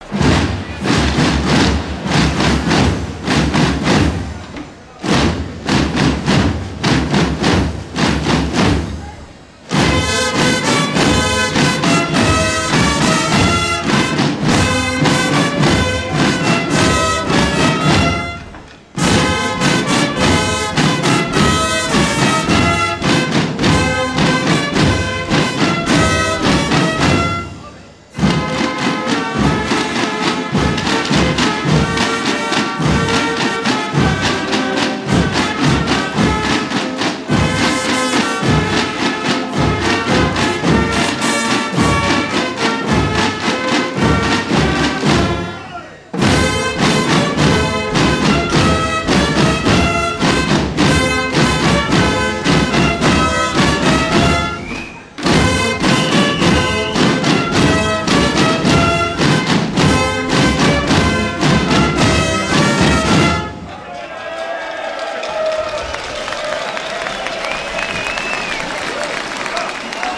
Fiestas de San Prudencio.